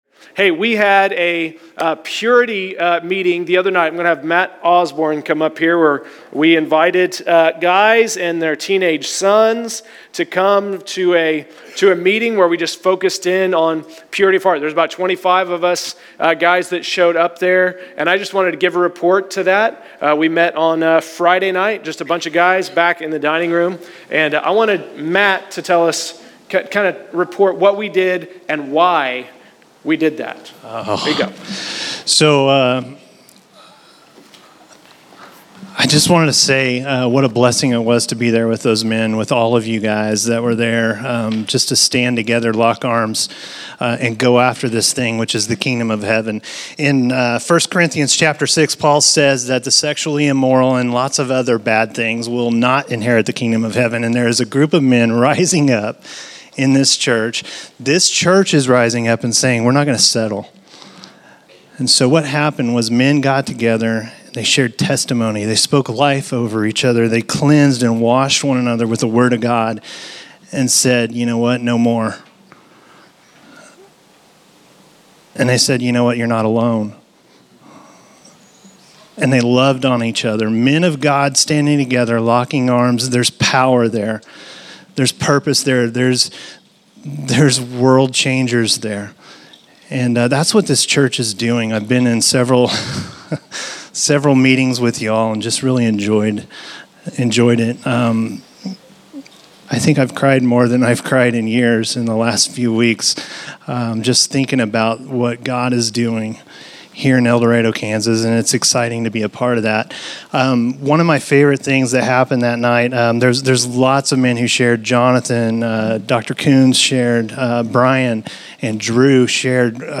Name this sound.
Category: Report